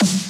b_snare1_v127l4o5c.ogg